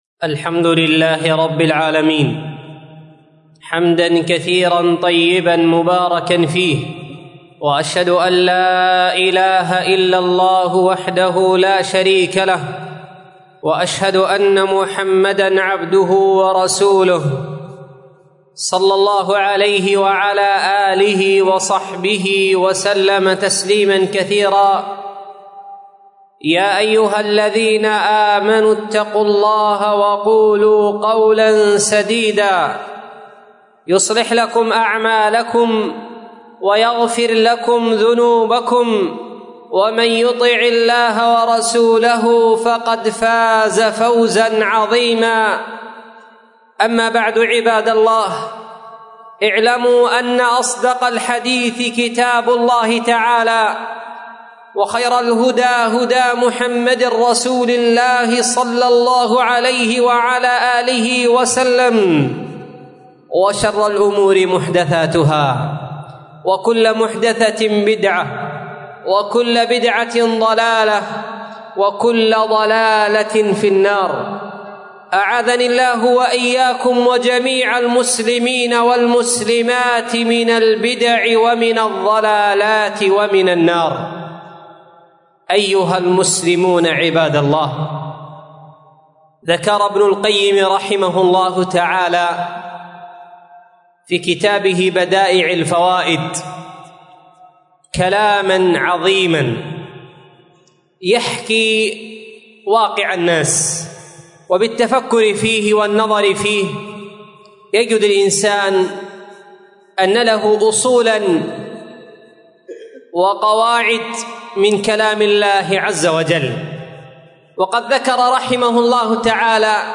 مسجد درة عدن / مدينة عدن حرسها الله 13 / جماد الأولى 1446